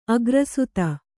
♪ agrasuta